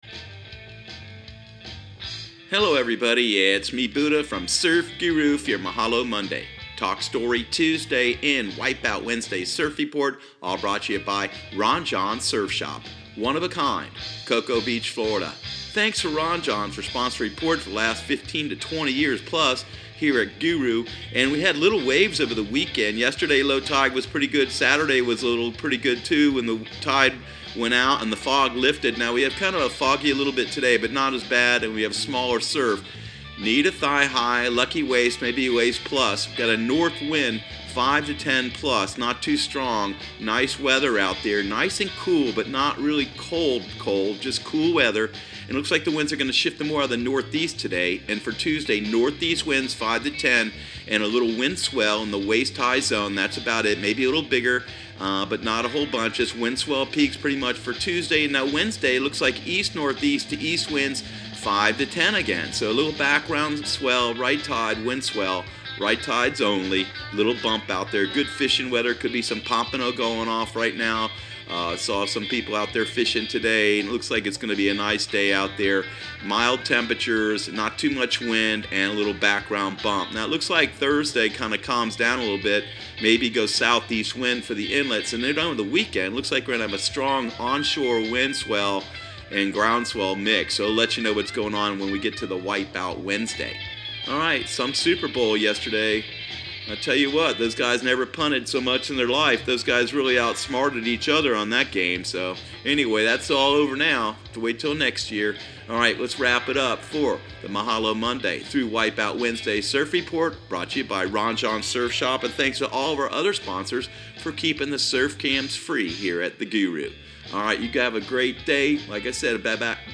Surf Guru Surf Report and Forecast 02/04/2019 Audio surf report and surf forecast on February 04 for Central Florida and the Southeast.